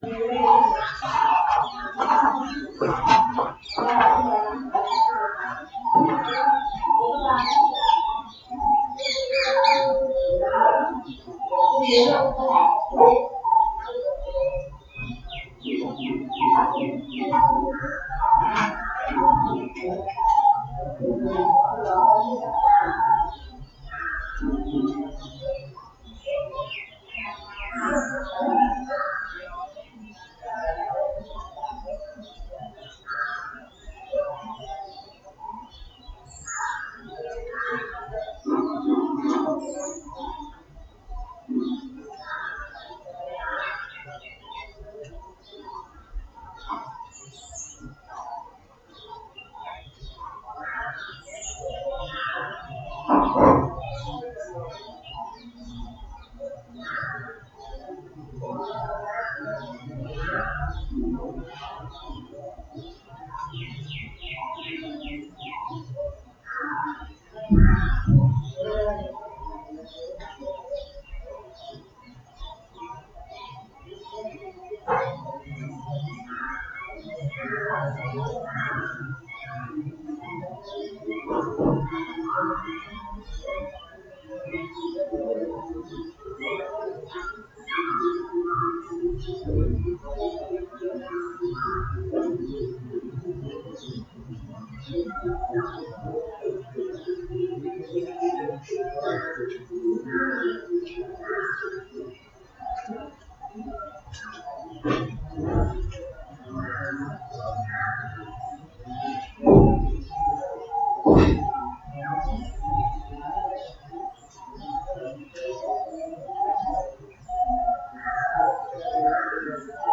recording of the sounds from the balcony. This is recorded by my laptop's built-in microphone so it is not the best quality, but you can hear several birds, dogs, monkeys (complaining about the dogs) and other stuff that is so different from Swedish summer sounds.